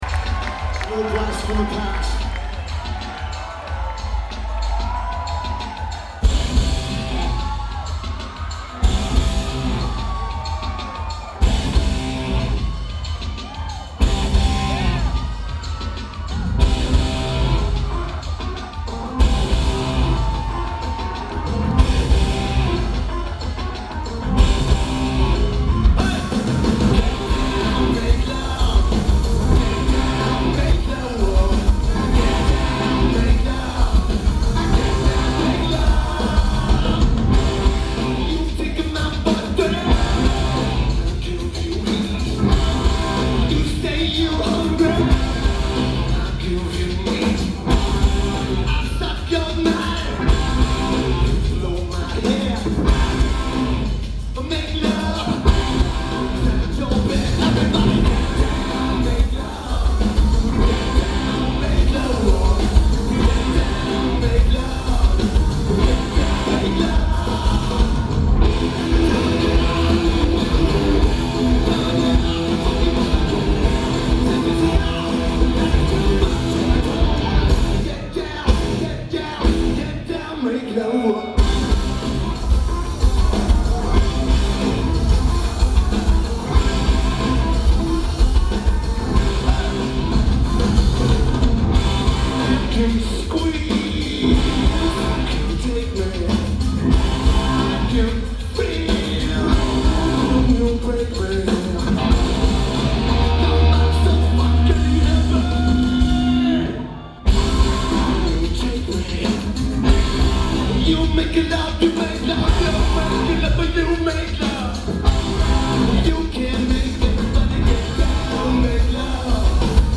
Henry Fonda Theater
Lineage: Audio - AUD (Schoeps MK4's + N-Box + Edirol R09)